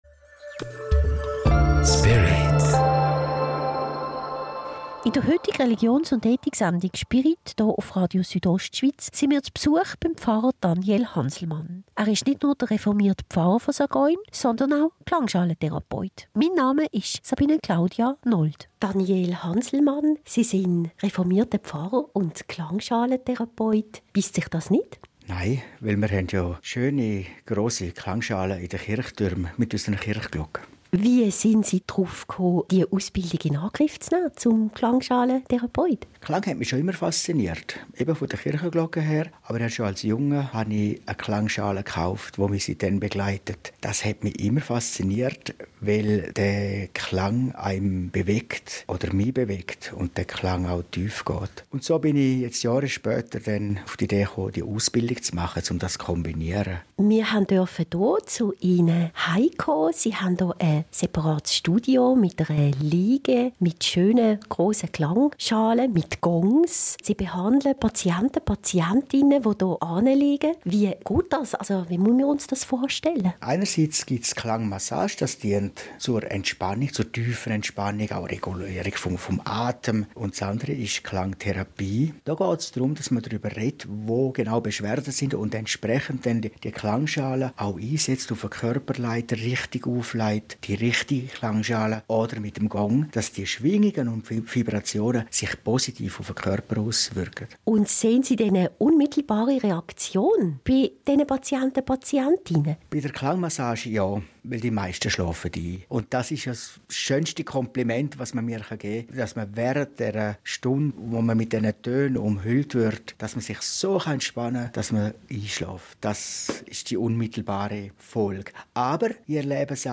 Spirit Klangschalen 28.8..MP3